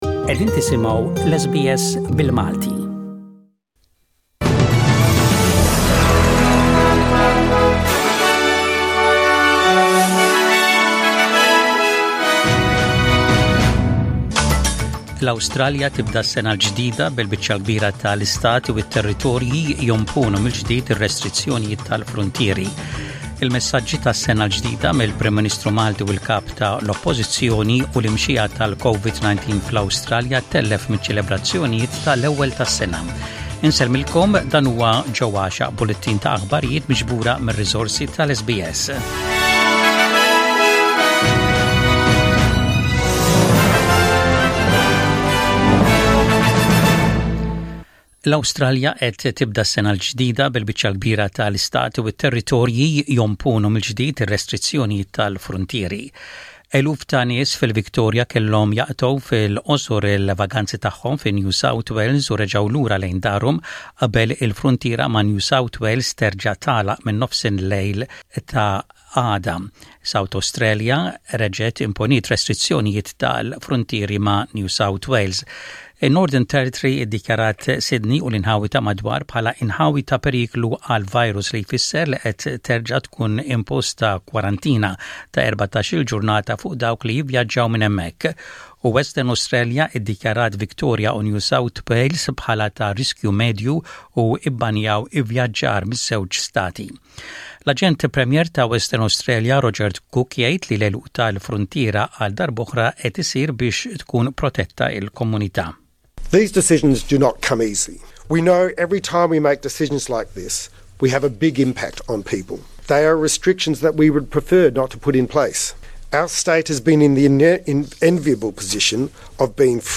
SBS Radio | Maltese News: 01/01/21